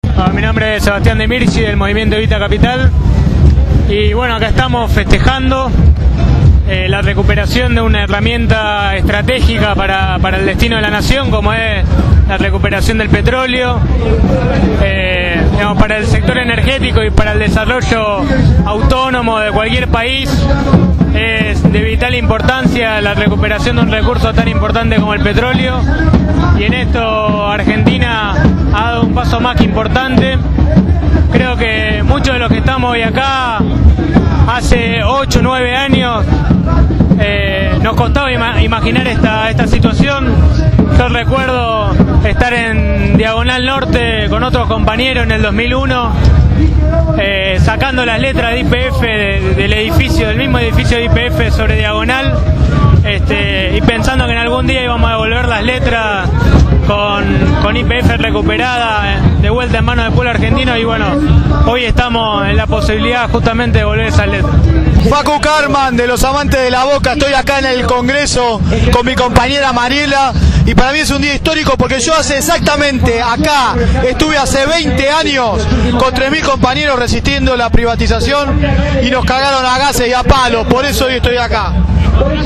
En una jornada histórica, en la concentración frente al Congreso en las últimas horas antes de la votación, compañeros/as de diferentes fuerzas políticas y sindicales dejaron sus palabras en Radio Gráfica.